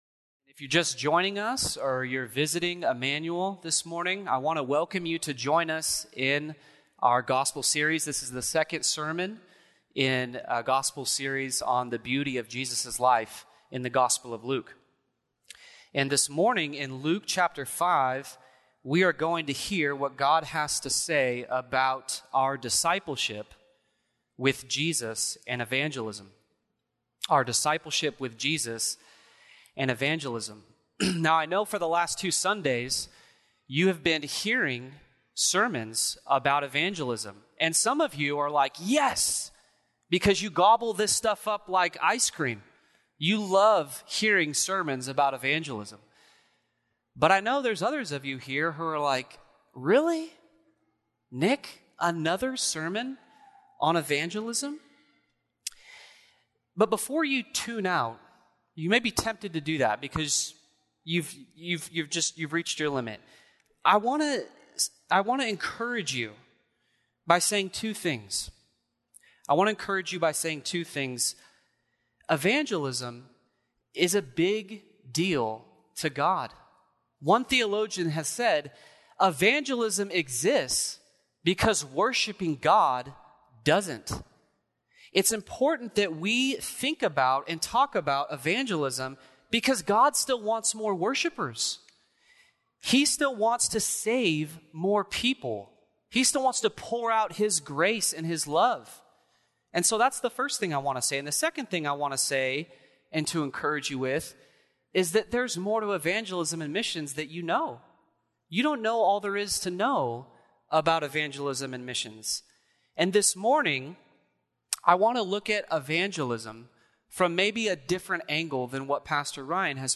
Sermons | Immanuel Baptist Church
Guest Speaker